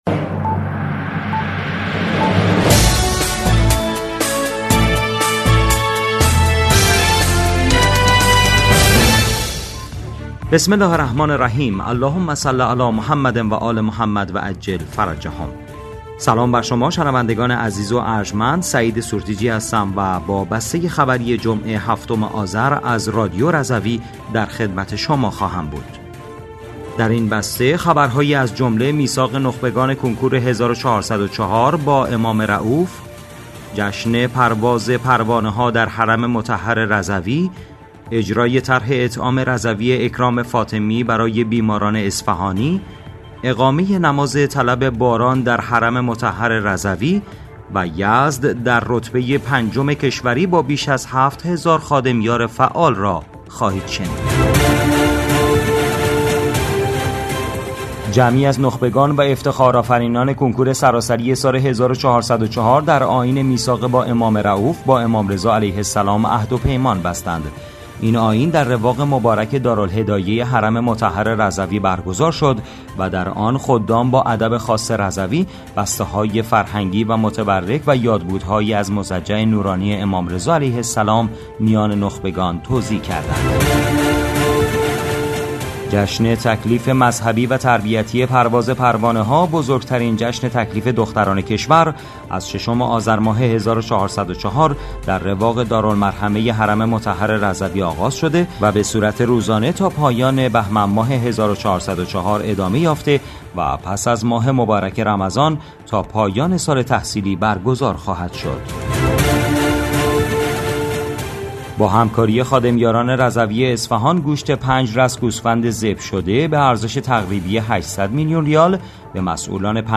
بسته خبری ۷ آذر ۱۴۰۴ رادیو رضوی؛